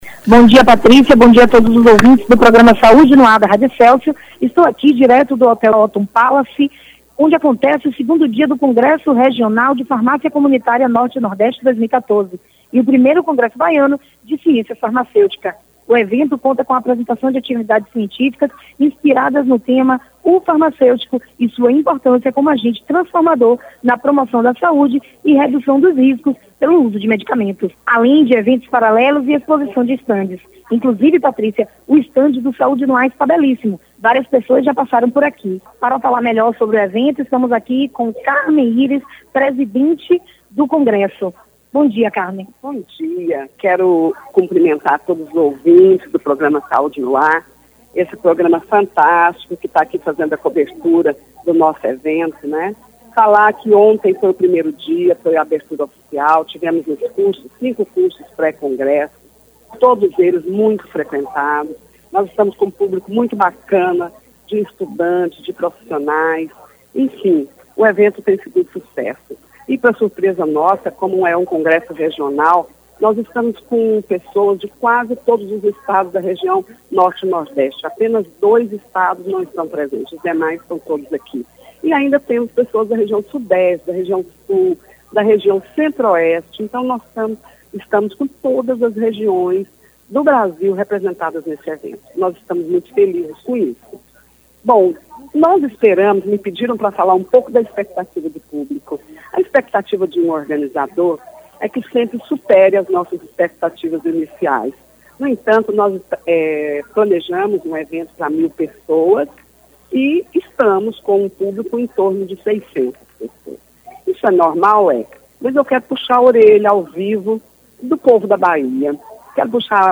Entrevista
O evento acontece no Hotel Bahia Othon Palace – no Rio Vermelho, em Salvador, e aguarda cerca de 1500 pessoas durante os três dias para discutir as atualidades, tendências, oportunidades e o futuro da profissão e do comércio farmacêutico.